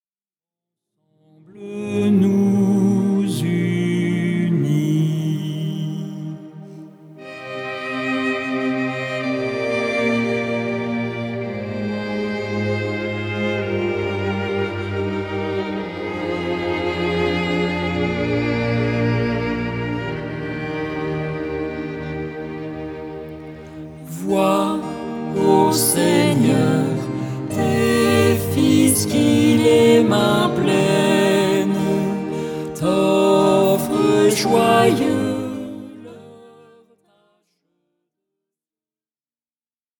chants liturgiques
Format :MP3 256Kbps Stéréo